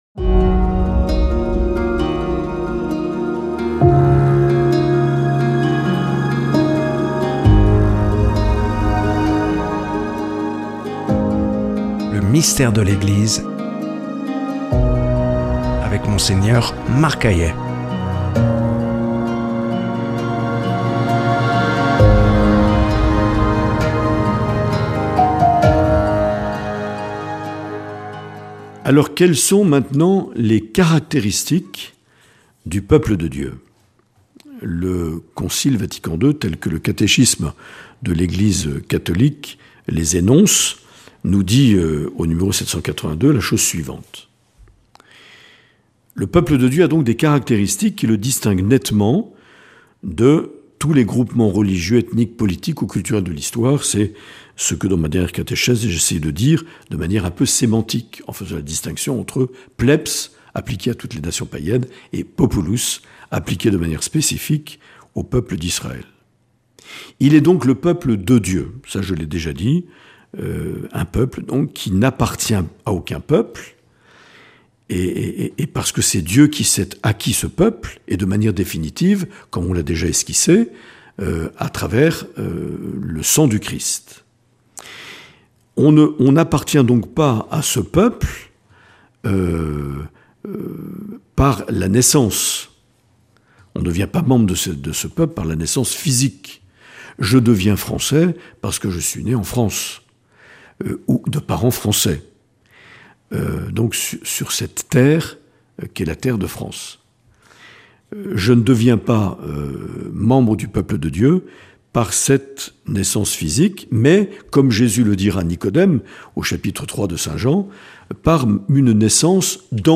Mgr Marc Aillet nous propose une série de catéchèses intitulée "Le Mystère de l’Eglise" notamment à la lumière de la constitution dogmatique "Lumen Gentium" du concile Vatican II.